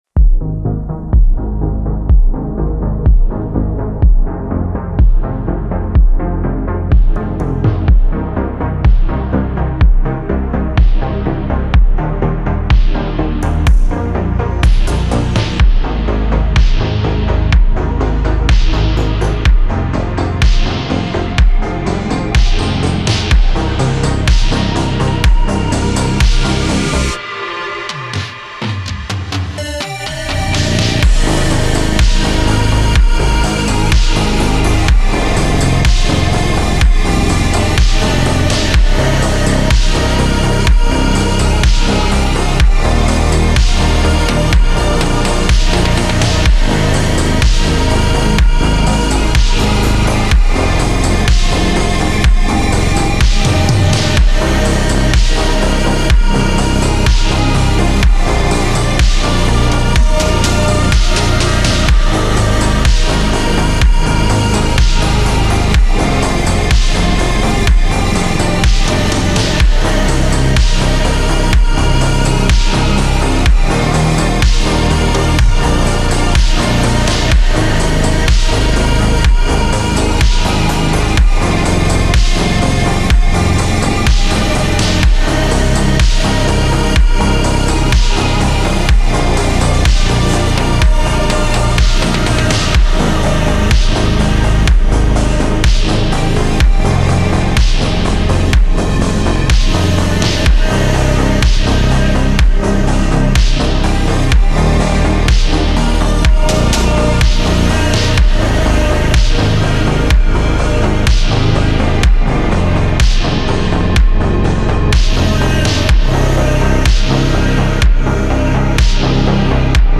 • Жанр: Trap